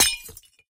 glass2.ogg